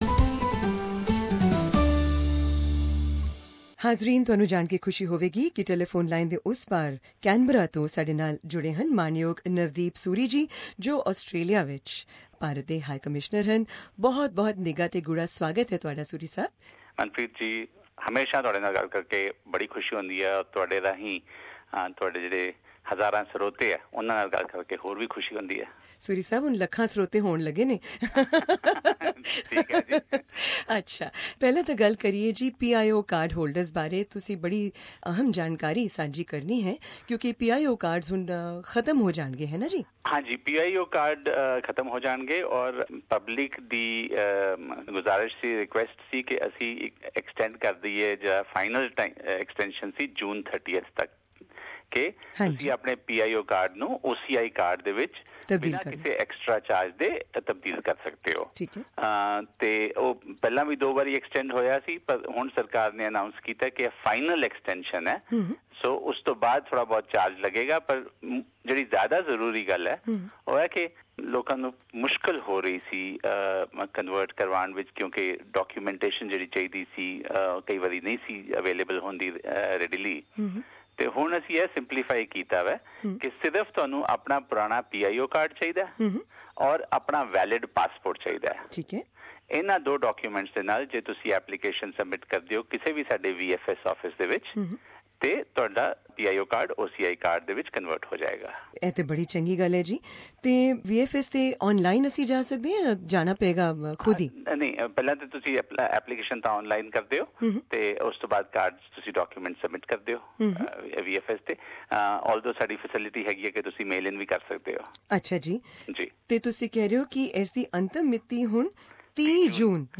Also in this interview, Mr Suri gave information about phasing out of PIO (Person of Indian Origin) card and the opportunity to convert them into OCI (Overseas Citizen of India) card for free, if the application is made before June 30, 2016.